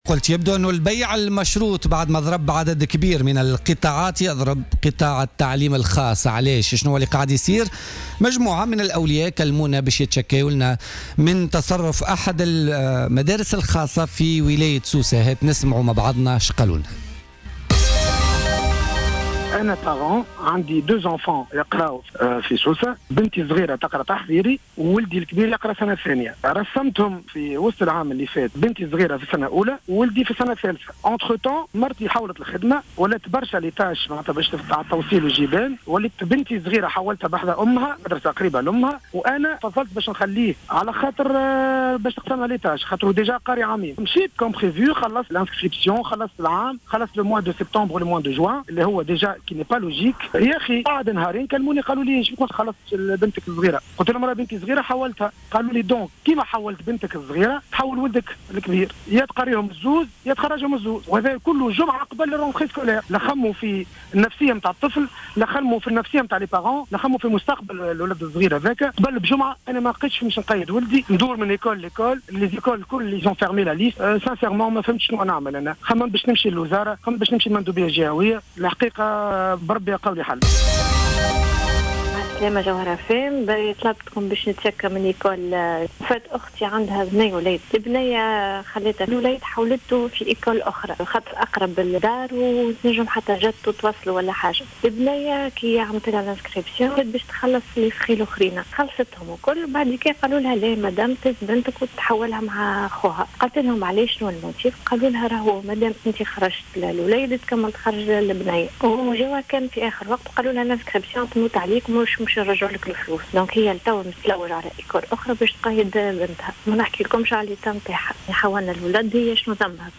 اتصل عدد من الأولياء بالجوهرة أف أم، للتعبير عن استيائهم من تصرفات صاحب مؤسسة تعليم خاصة في ولاية سوسة، اتهموه بـ"البيع المشروط" مطالبين سلطة الإشراف بالتدخل ووضع حد لمثل هذه الممارسات قبل أن تنتقل عدواها إلى مؤسسات تعليمية أخرى.